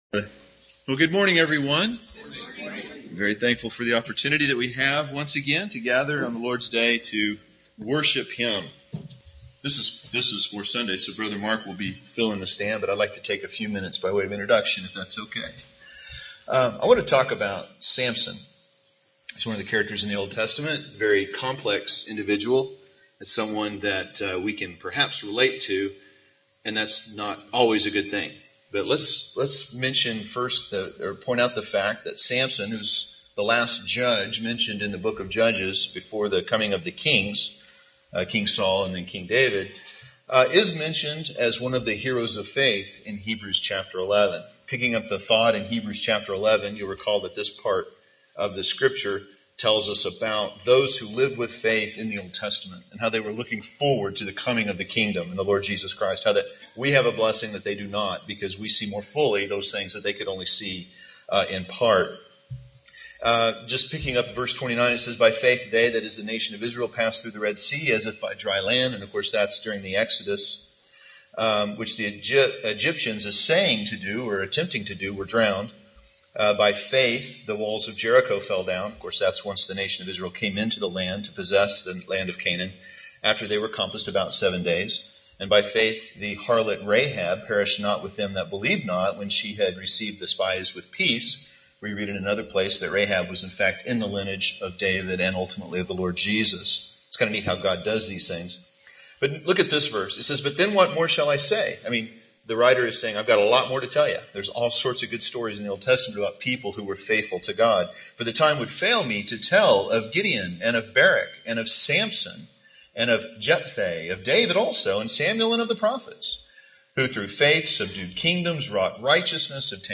Note: Easter Sunday 2013